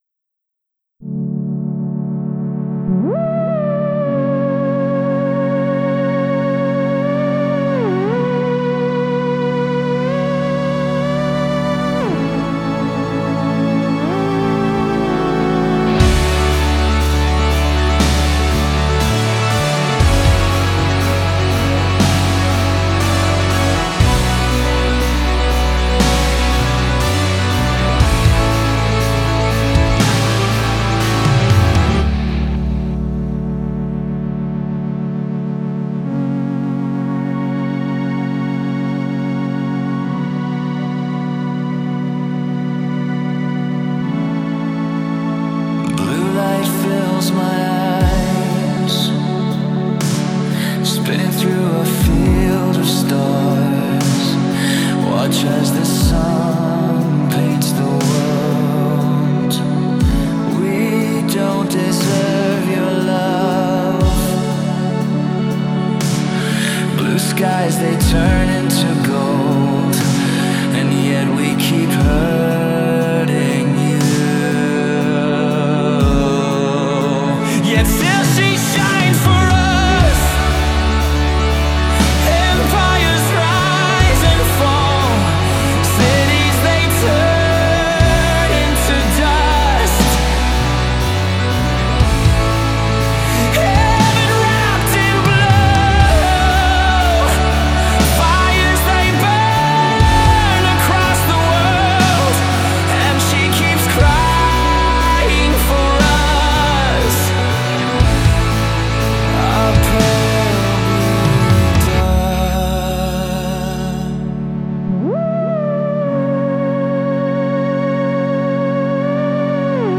The exception is the vocals that for now is an AI placeholder.
I used a mix of guitar and my own voice to record the melody and had AI replace my singing to get a feel of what it'll sound like.
During the verse it's just a digital snare but I wanted to use accoustic drums in the chorus. I tried mixing in a digital snare with the accoustic one in the chorus to make it a bit fatter.
I really like the feel of the organ, but does it get to messy in the chorus?